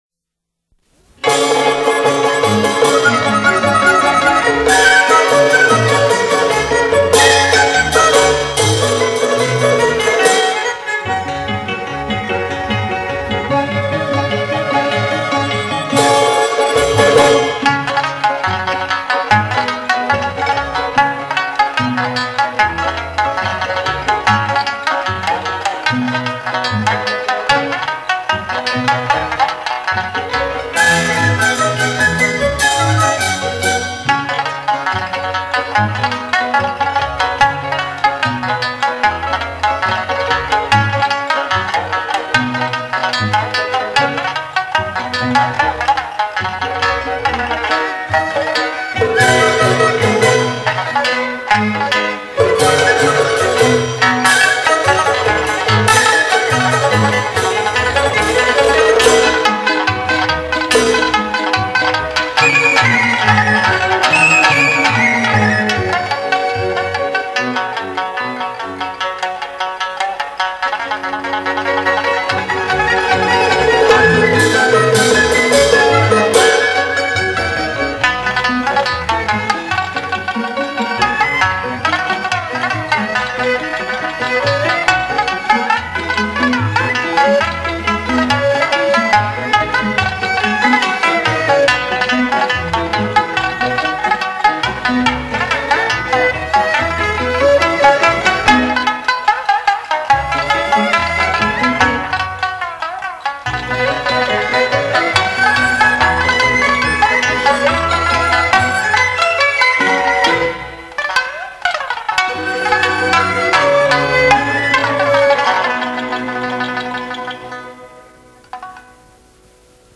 以琵琶、三弦、古筝、古琴、阮、柳琴等常见的几种弹拨乐器
演译中国民族音乐的经典，首首动听、曲曲畅销
三弦